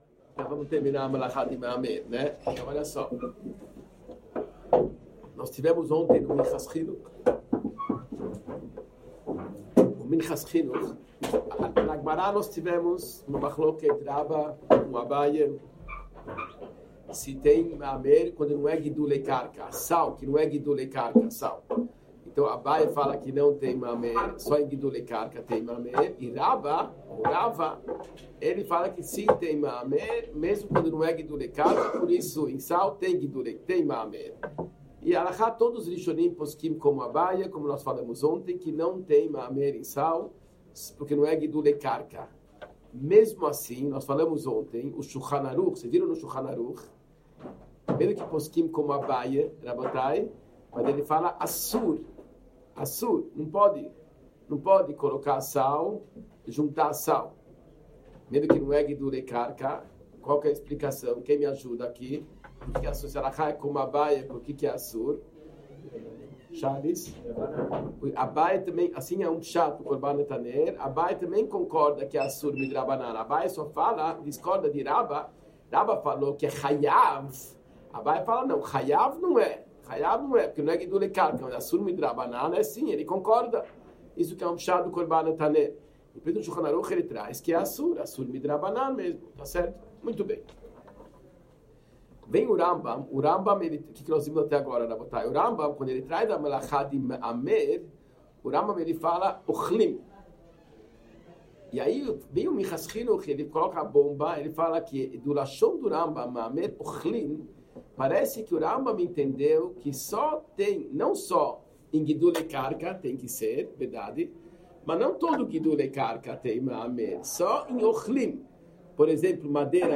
Shiur 39 Melachot – Parte 21